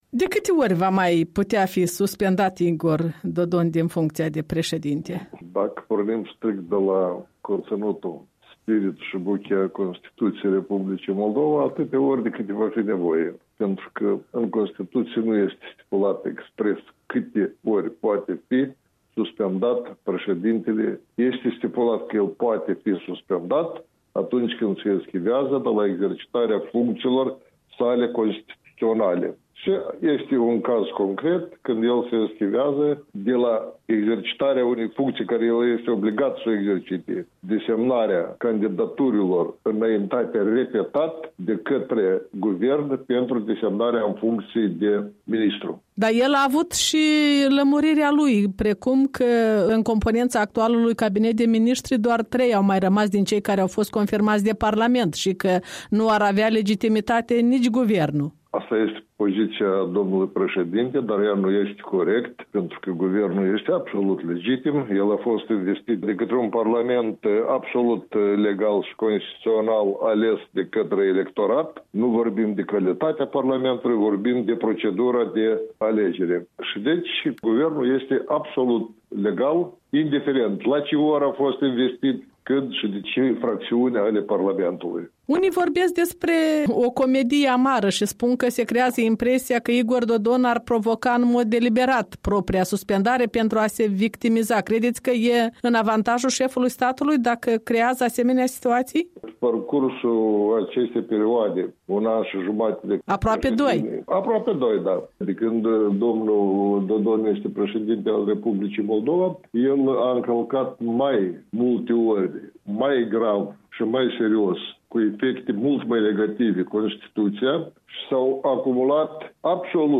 Interviu cu fostul judecător la Curtea Constituţională.